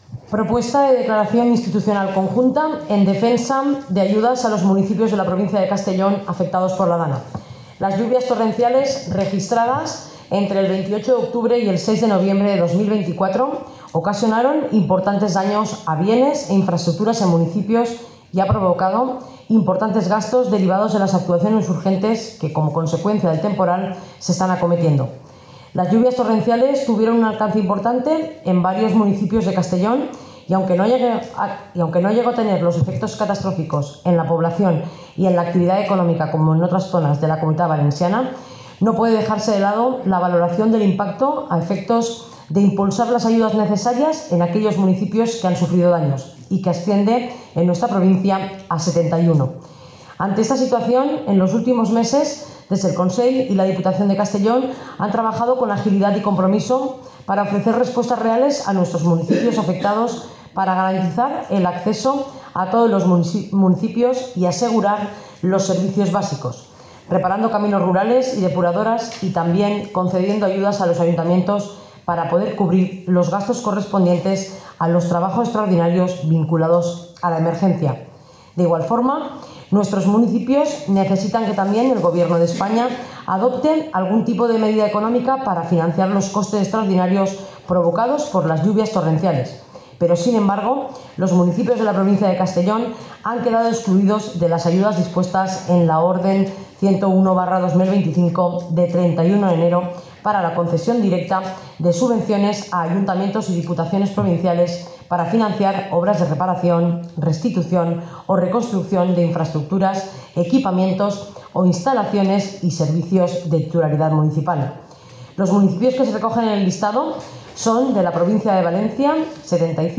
Presidenta-Marta-Barrachina-Pleno-febrero-.mp3